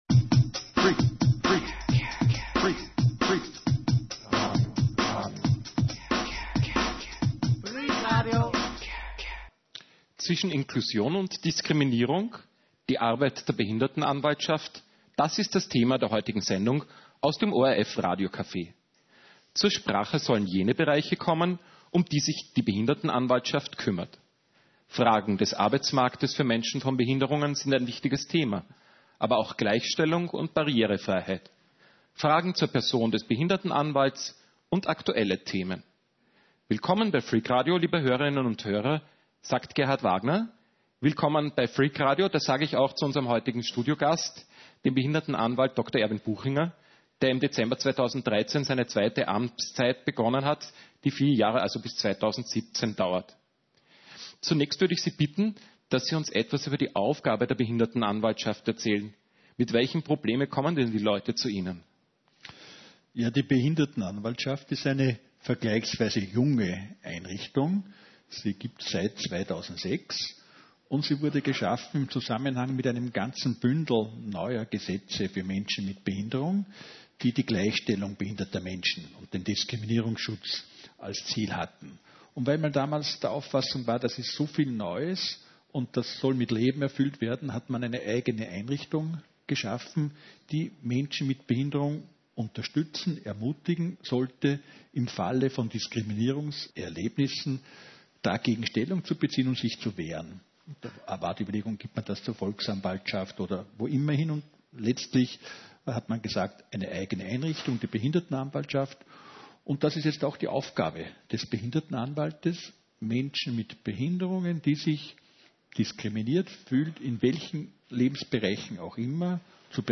Behindertenanwalt Erwin Buchinger zu Gast bei Freak-Radio.
Zwischen Inklusion und Diskriminierung – die Arbeit der Behindertenanwaltschaft, das ist das Thema der heutigen Sendung aus dem ORF-RadioCafe. Zur Sprache sollen heute jene Bereiche kommen, um die sich die Behindertenanwaltschaft kümmert: Fragen des Arbeitsmarktes für Menschen von Behinderungen sind ein wichtiges Thema, aber auch Gleichstellung und Barrierefreiheit, Fragen zur Person des Behindertenanwalts und aktuelle Themen.